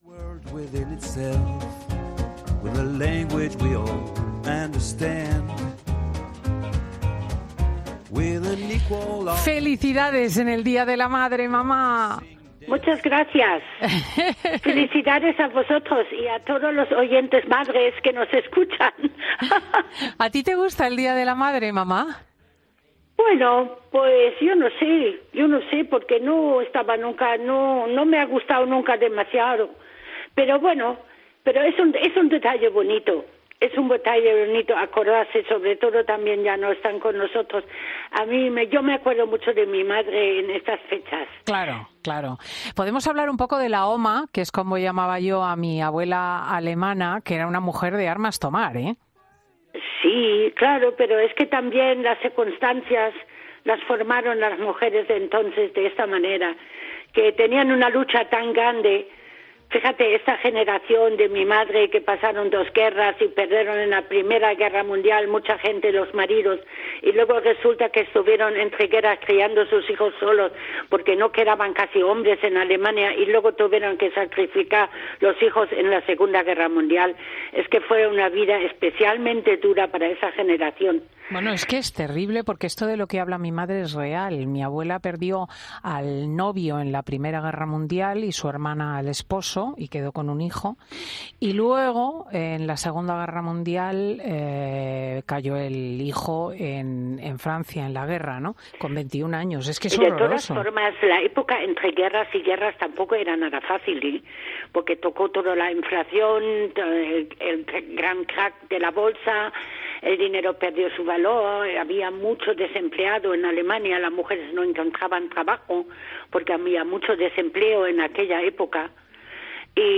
A ella la ha querido felicitar directamente a través de los micrófonos, mientras ella explicaba que no le gusta de manera especial este día, aunque lo aprovecha para "acordarse de todos los que no están con nosotros, especialmente de mi madre".